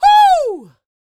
D-YELL 2101.wav